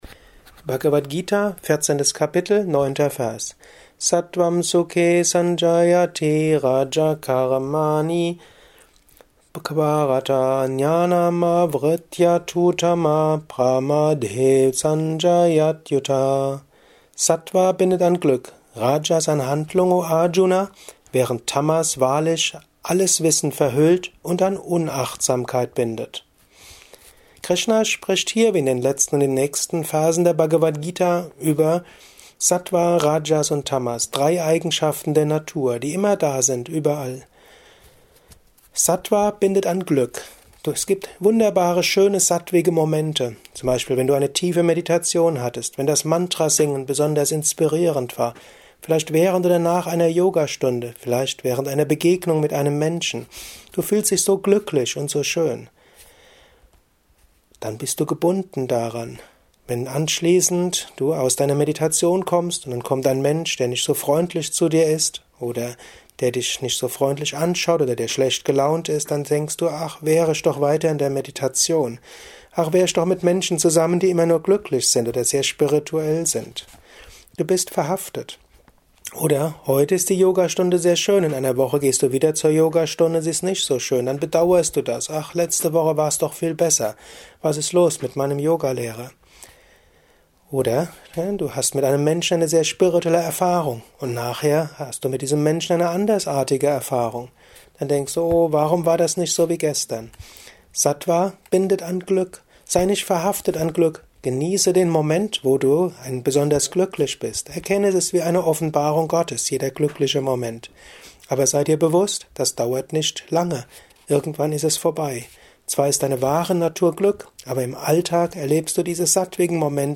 Dies ist ein kurzer Kommentar als Inspiration für den